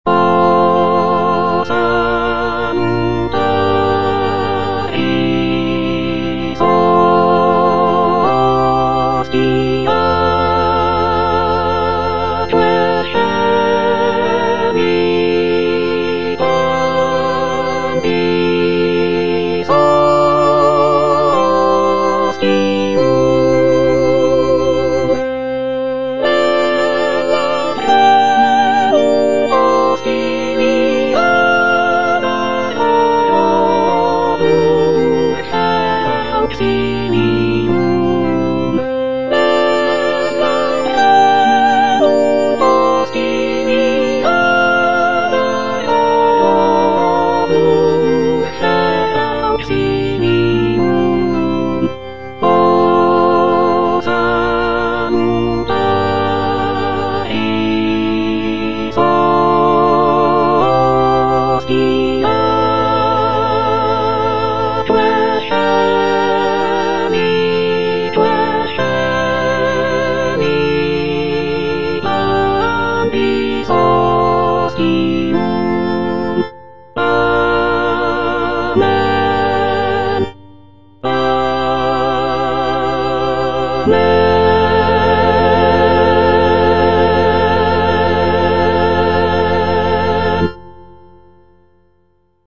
T. DUBOIS - MESSE IN F O salutaris hostia - Soprano (Emphasised voice and other voices) Ads stop: auto-stop